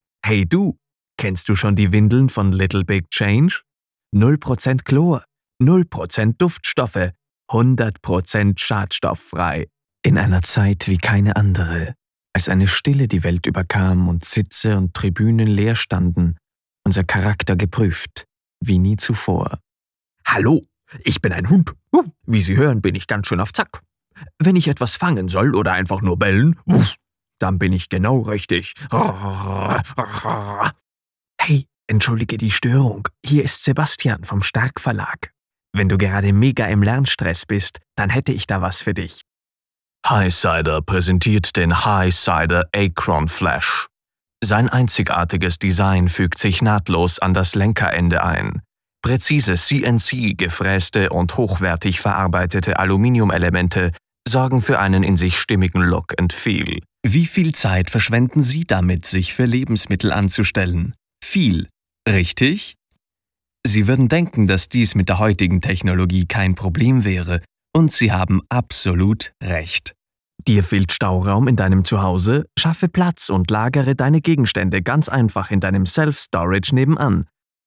Alemán (Austria)
Dulce
Cálido
Confiable